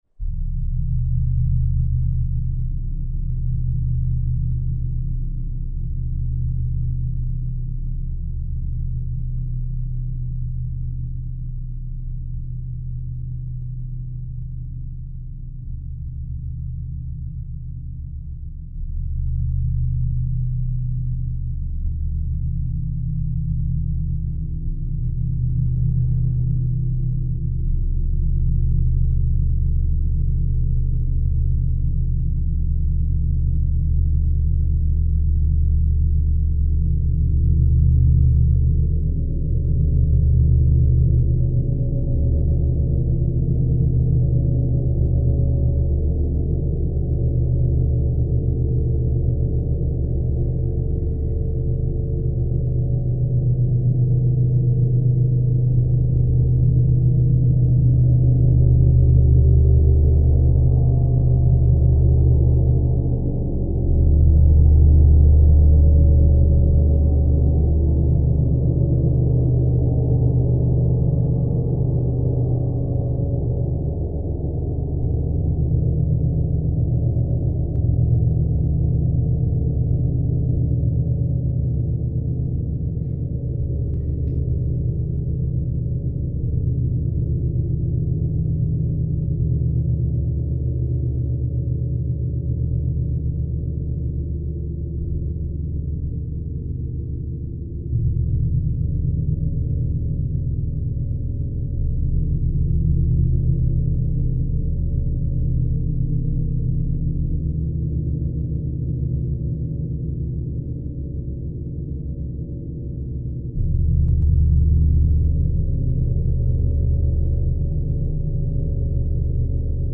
Esta grabación es la real del Gong disponible
Gong Sinfónico 85cm